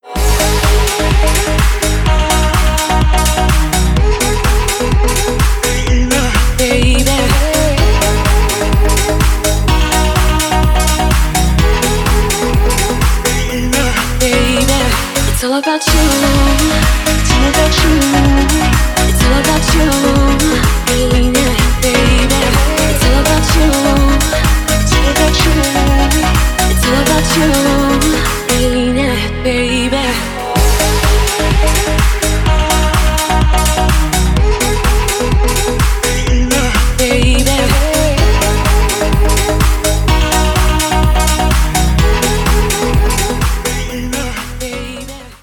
• Качество: 320, Stereo
dance
Club House